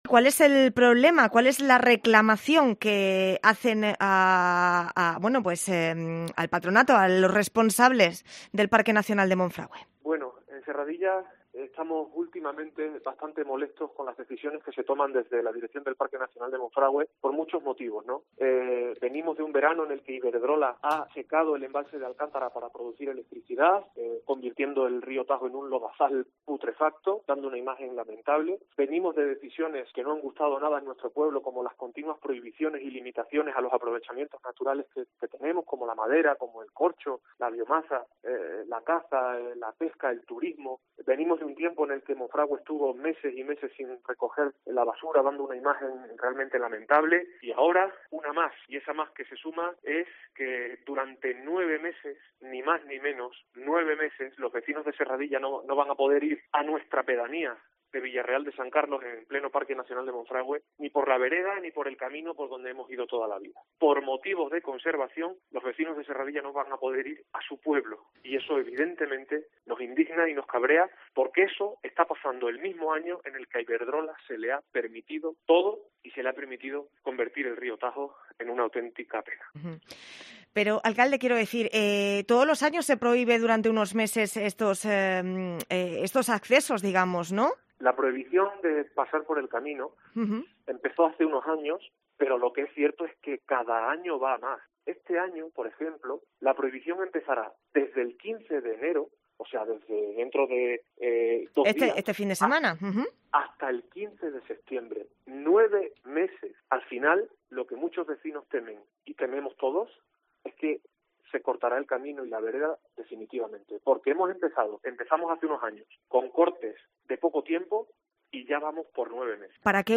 En COPE Cáceres hablamos con el alcalde de Serradilla, Francisco Sánchez Vega